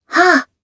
Kara-Vox_Attack4_jp.wav